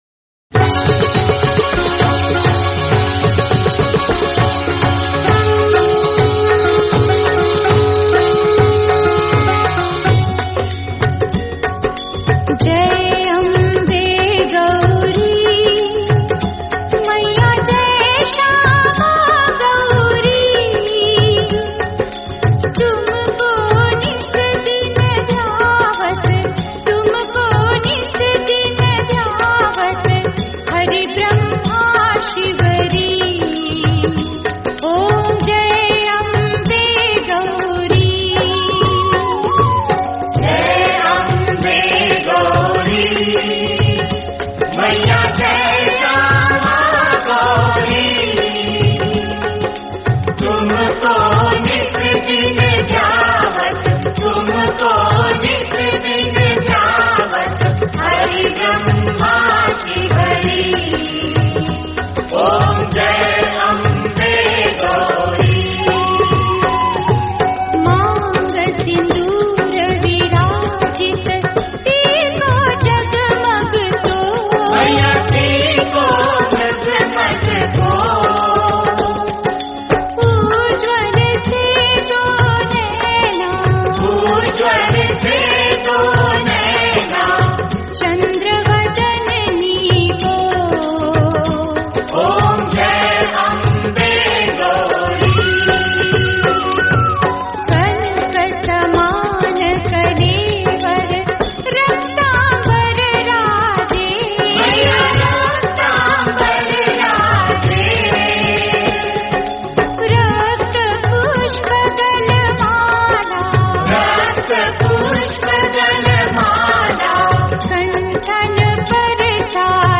Devi Aarti
Soothing
JaiAmbeGauriAarti.mp3